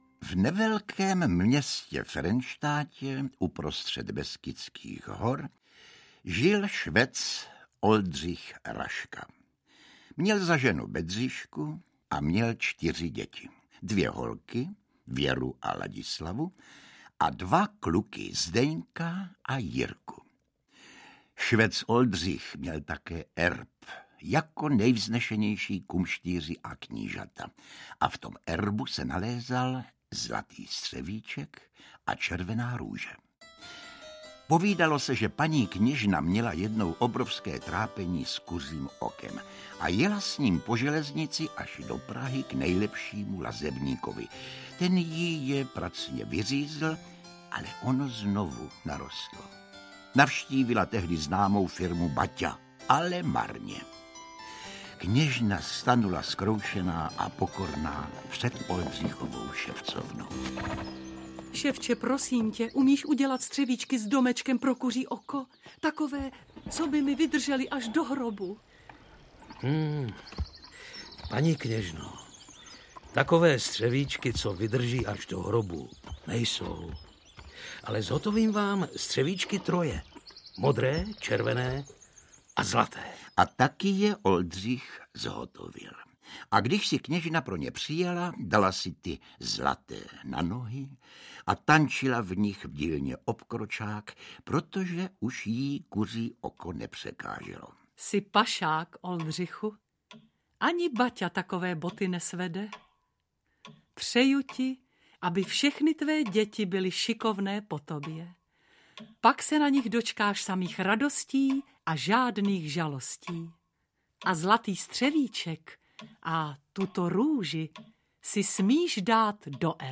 Pohádka o Raškovi audiokniha
Ukázka z knihy